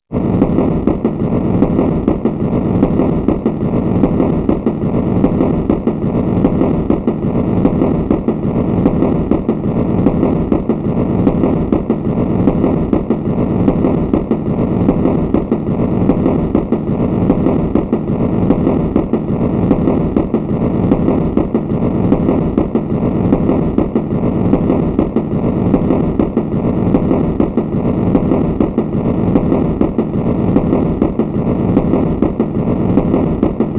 الالات واصوات